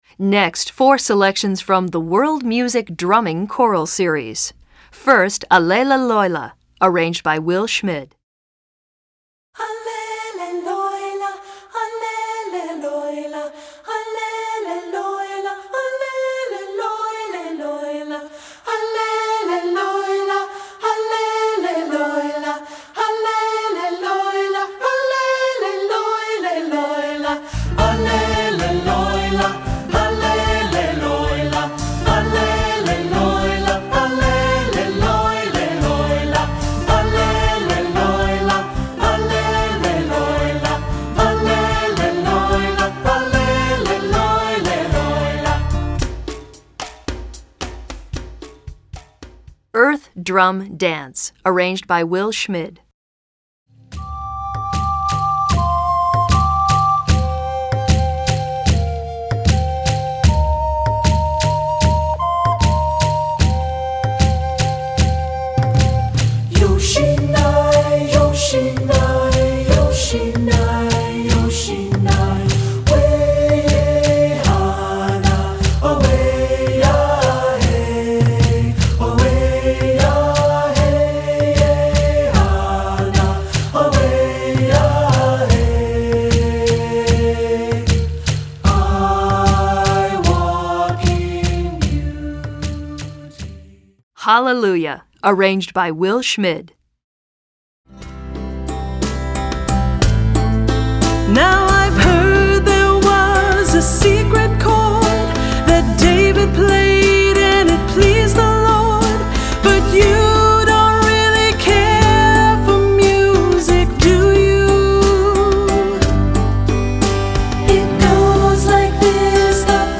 Voicing: 5-Part Any Combination